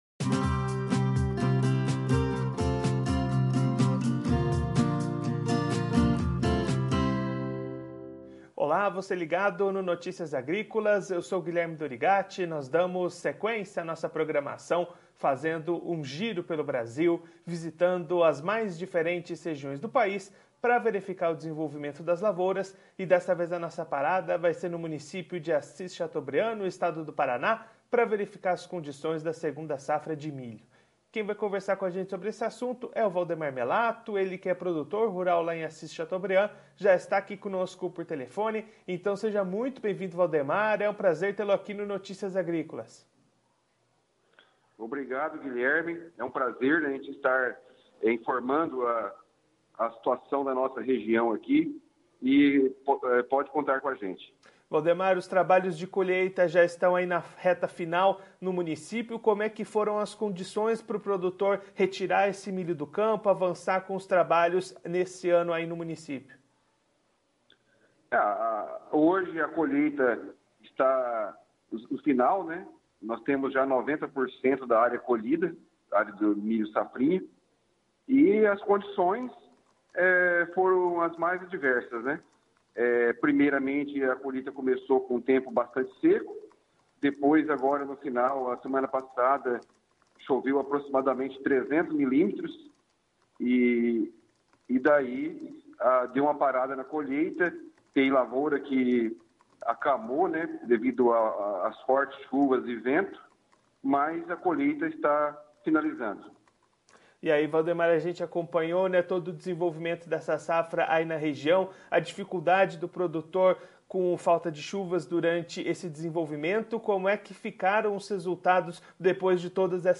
Confira a entrevista completa com o produtor rural de Assis Chateaubriand/PR no vídeo.